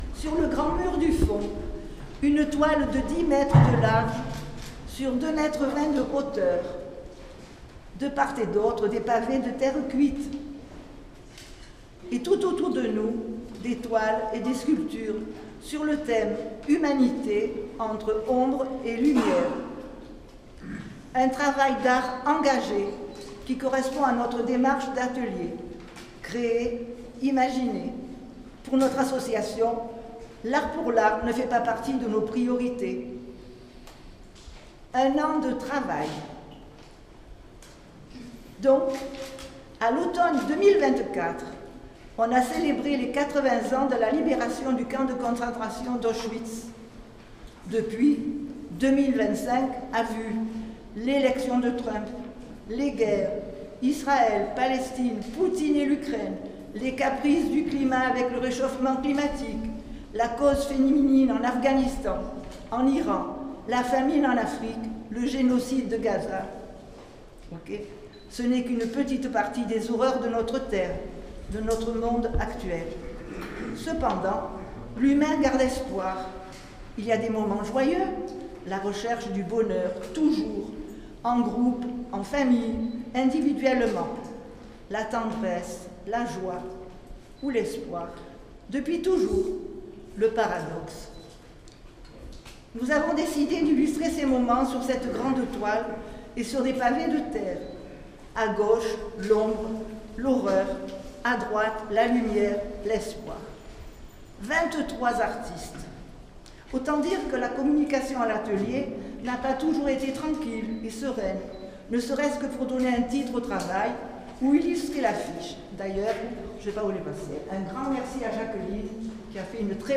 un ENREGISTREMENT fait le soir du vernissage